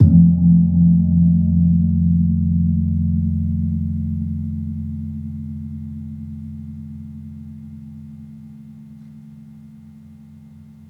Gamelan
Gong-F2-f-p.wav